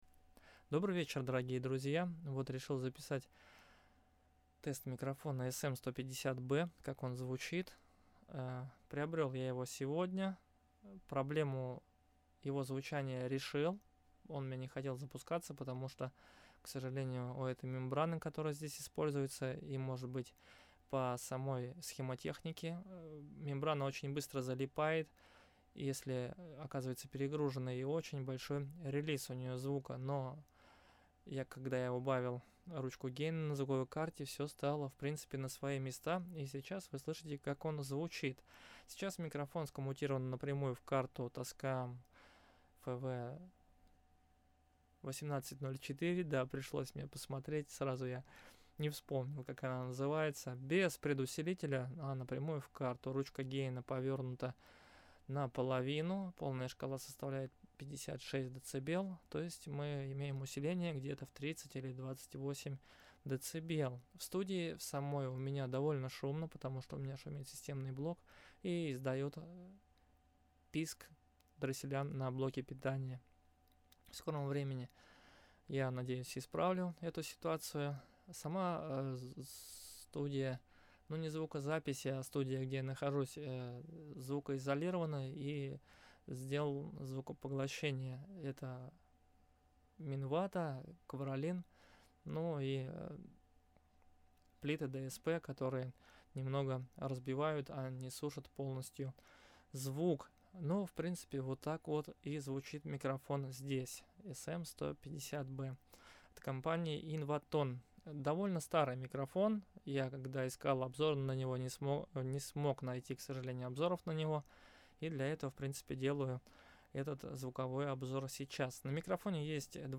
У меня же проблема: Звук тихий,много шума, при этом ручка гейна звуковой...
Никакой обработки и гейна из DAW Вложения микро.mp3 микро.mp3 9,7 MB · Просмотры: 1.188